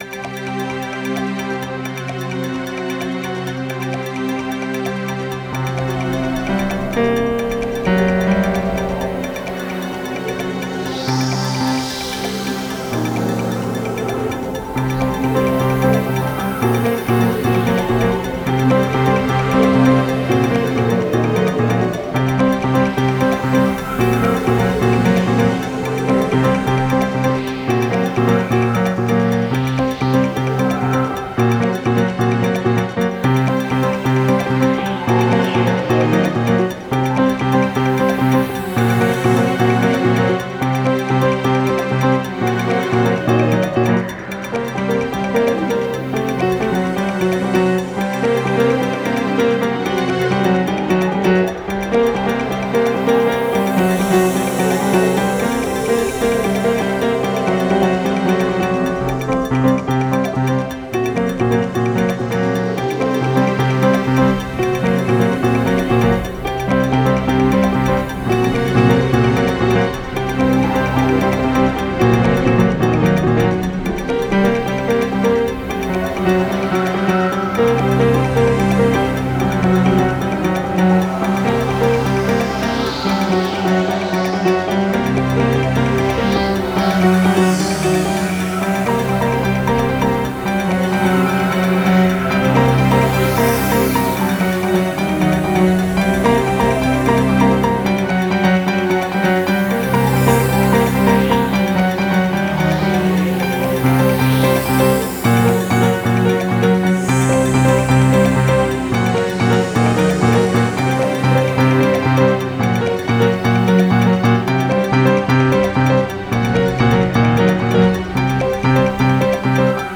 Tempo: 130 bpm / Datum: 27.03.2017